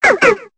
Cri de Polarhume dans Pokémon Épée et Bouclier.